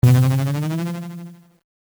power_button2.mp3